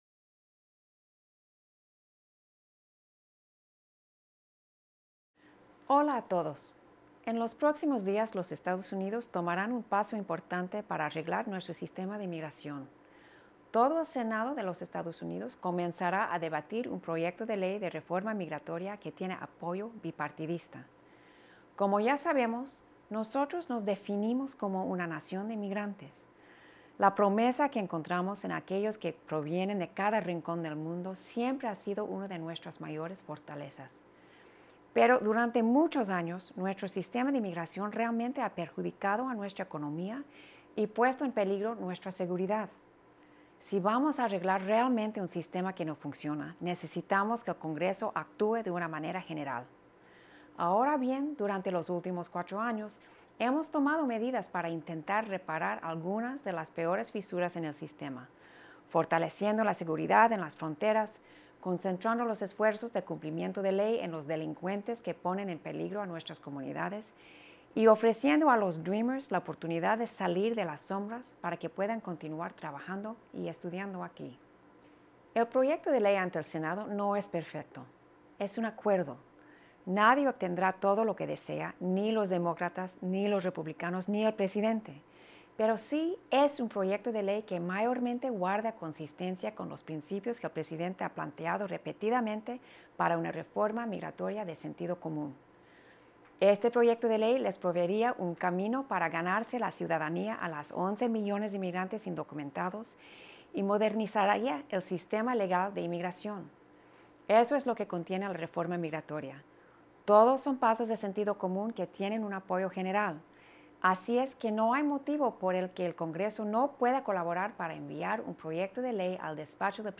En el mensaje de esta semana, Cecilia Muñoz habló sobre el comienzo del debate del proyecto de ley de reforma migratoria en el Senado la próxima semana. Habló sobre como este proyecto tiene un fuerte apoyo bipartidista y sobre la importancia de que el Congreso actúe conjuntamente para arreglar el sistema migratorio.